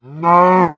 cow
hurt3.ogg